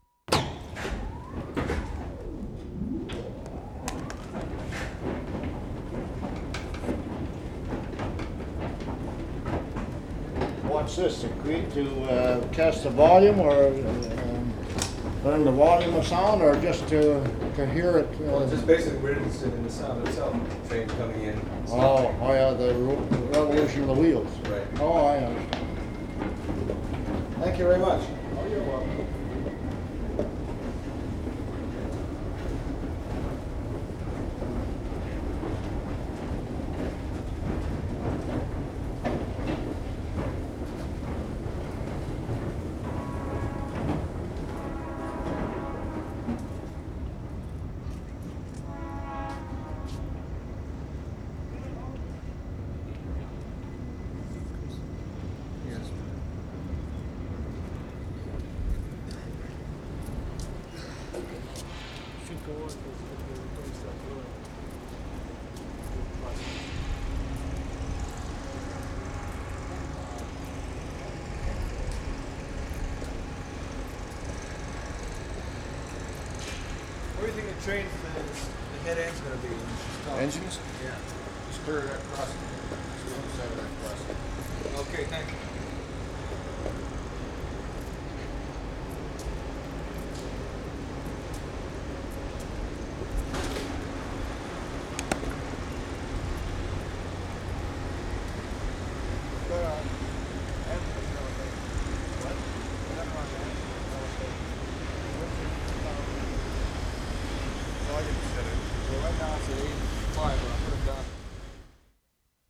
WORLD SOUNDSCAPE PROJECT TAPE LIBRARY
CP STATION, conveyor motor 1'50"
1. Pleasant motor sound. Recordists are getting ready to record, attendant checking them out.